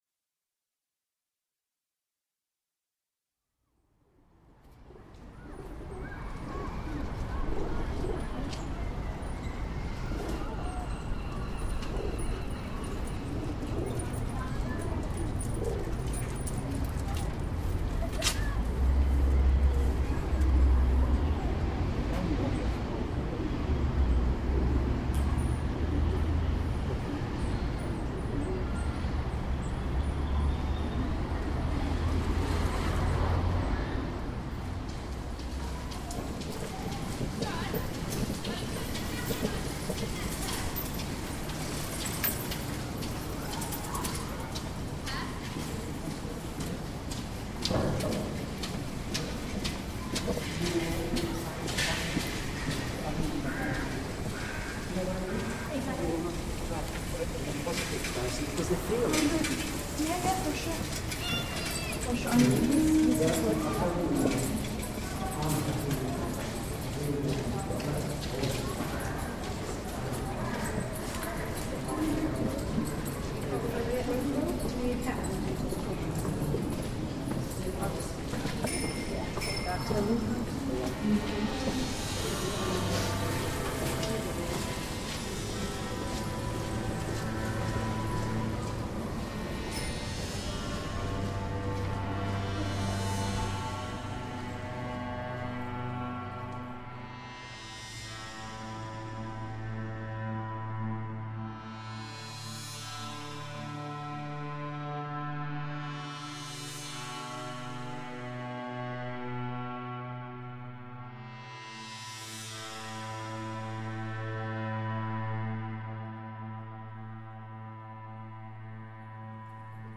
site specific installation commissioned by camden 'new wave' festival november 2013
over a period of 50 days, field recordings were collected at locations along locations of the regents canal from primrose hill to kings cross.
towpath users were invited to help in the creation of the work by contributing their own sound recordings via mobile phone or online.
the sound clips were then reworked using a series of perameters and loops set in generative software to create gestures, patterns and rhythms, inspired by elements of traditional canal song.